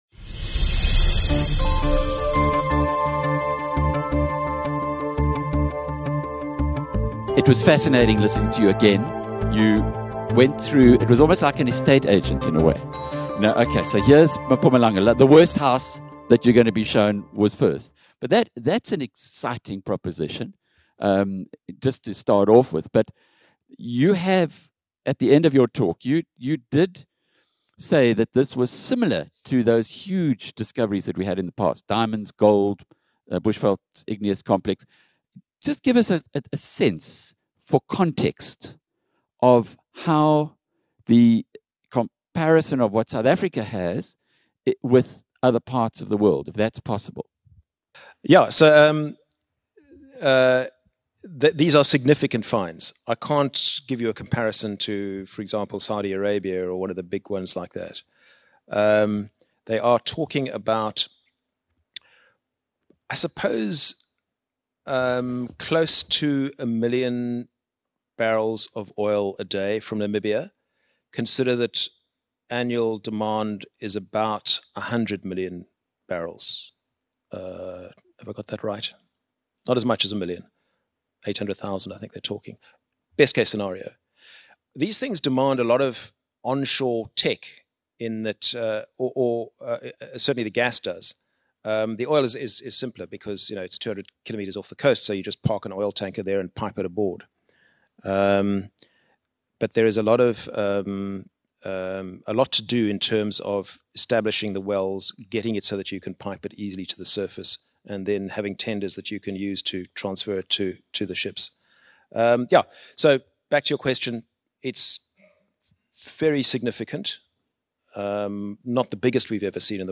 22 May BNC London: Lorimer Q&A - How SA can unlock its vast oil and gas potential
In a dynamic Q&A between Alec Hogg and James Lorimer, the shadow minister of Mineral Resources, the discussion spans from Namibia's significant oil finds to South Africa's energy landscape. Lorimer highlights potential job creation and revenue influx from oil and gas, while acknowledging policy hurdles. With insights into fracking, renewable energy, and electoral prospects, the dialogue offers a comprehensive view of energy and politics.